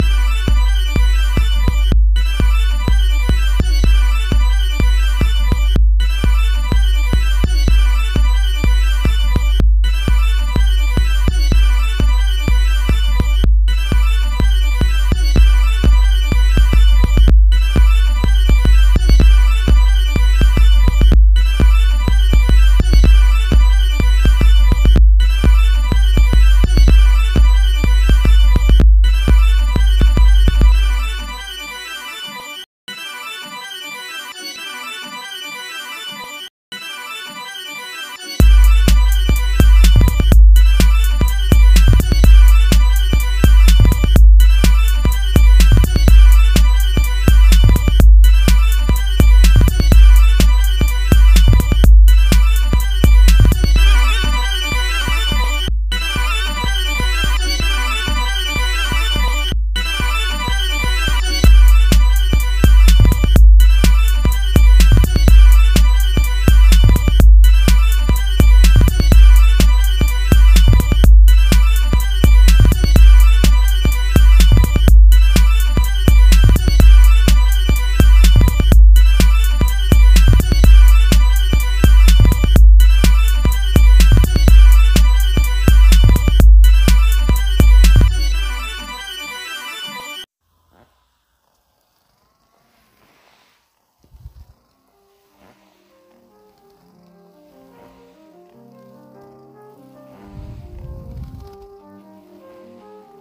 넘 튀네요
비트 나쁘지않은데요 레이지느낌
비트의 킥이 살짝 이상하거덩요
아 뭔가 오류가 난 거 같아요 ㅠㅠ 드럼이 이상하네